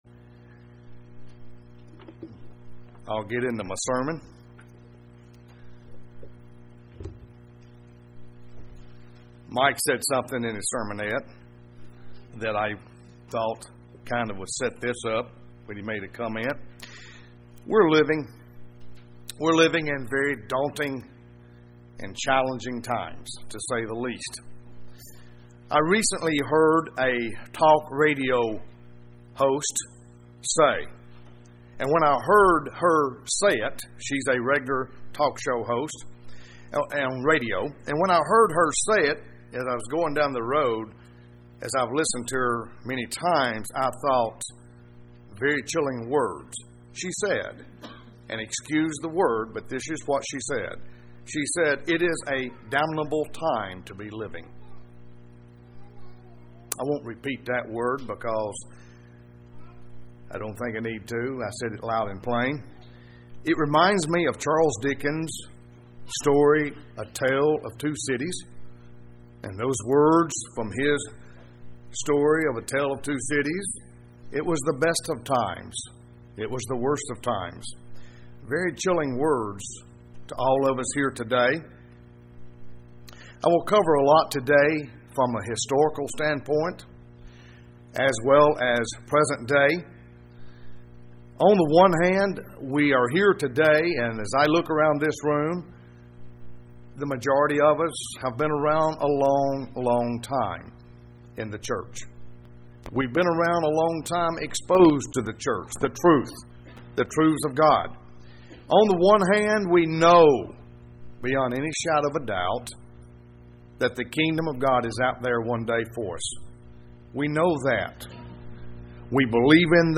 Given in Tupelo, MS
UCG Sermon Studying the bible?